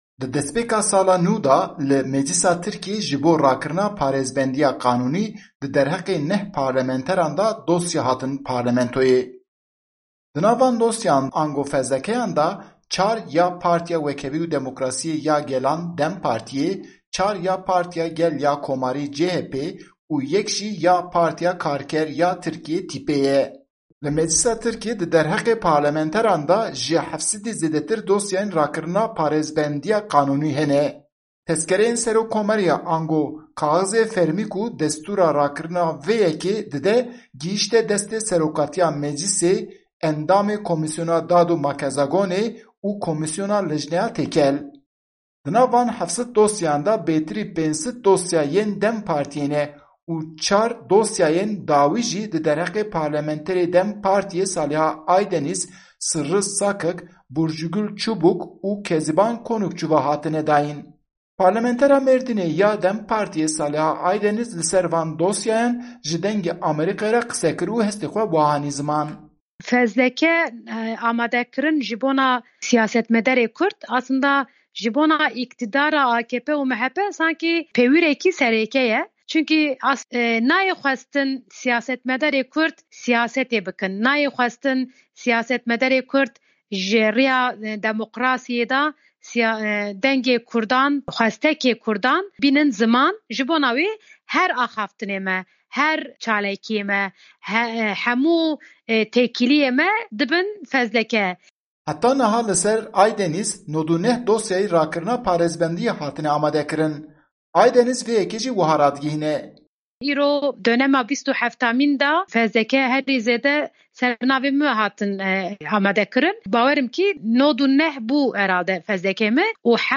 Parlementera Mêrdînê ya DEM Partîyê Salîha Aydenîz li ser van doseyan ji Dengê Amerîkayê re qise kir.